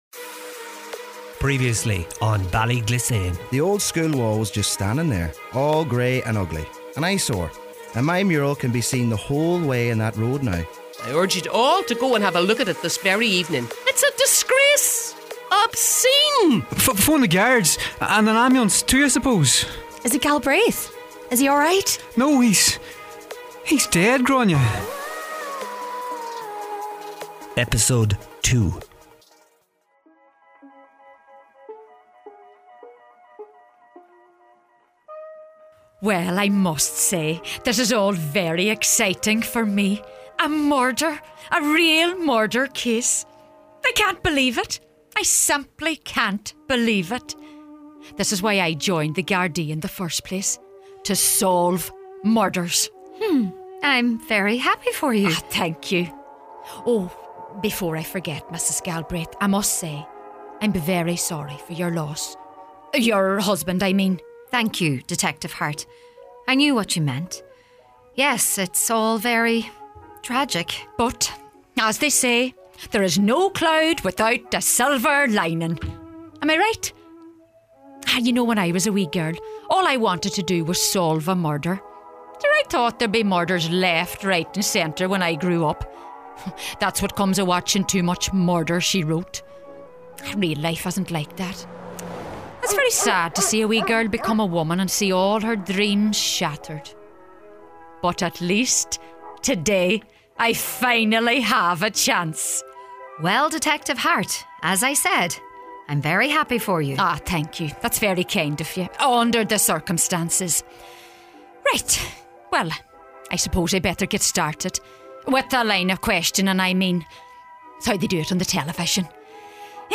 A drama series set in a small town somewhere in the heart of Donegal, where we meet various people who live in Ballyglissane and learn about their lives, their problems, and their secrets.